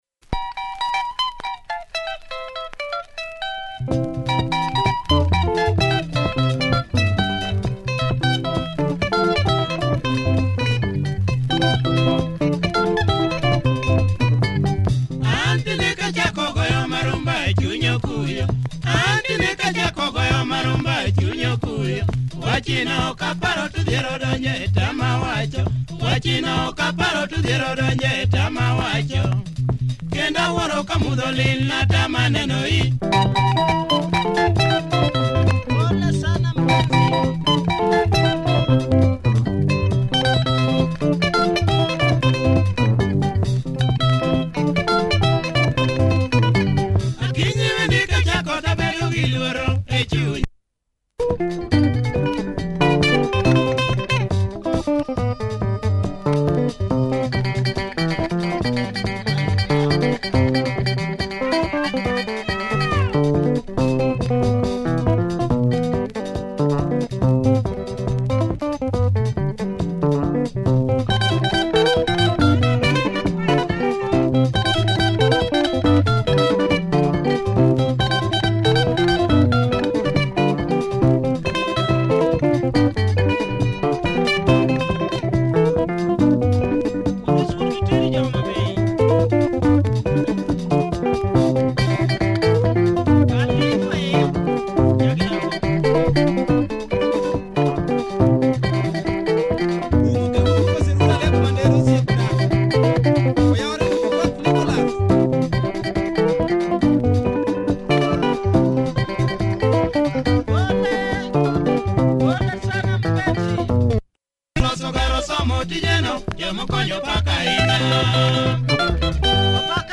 Great Luo benga from this prolific group